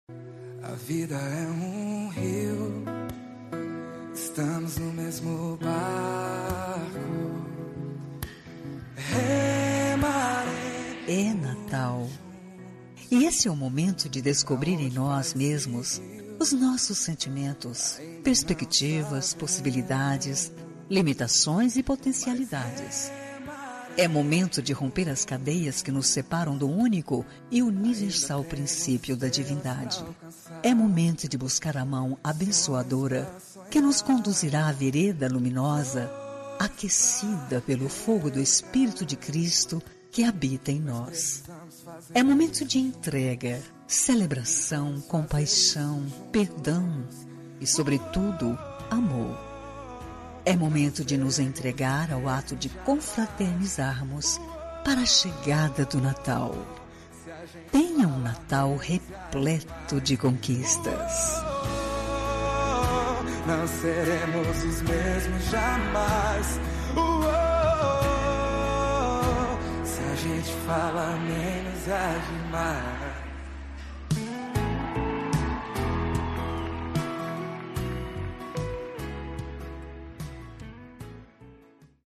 Natal Pessoa Especial – Voz Feminina – Cód: 348991